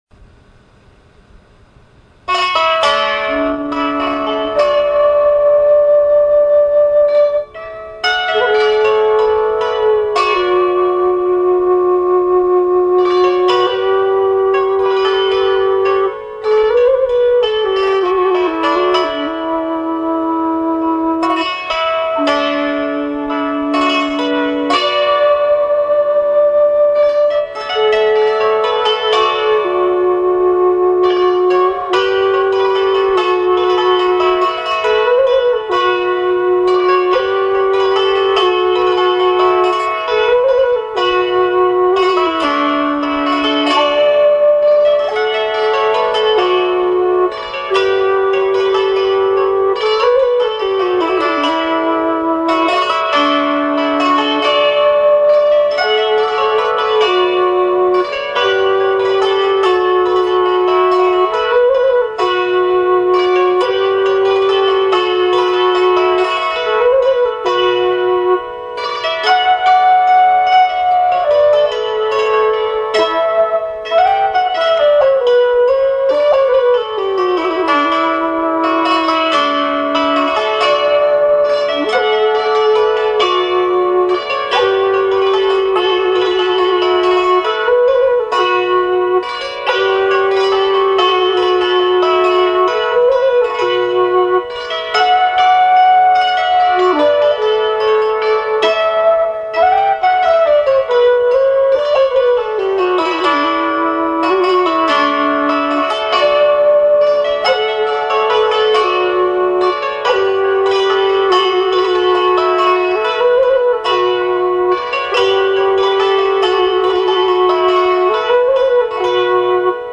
Hammer Dulcimer